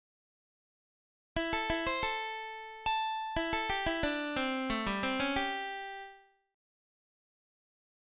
Blues lick > lick 8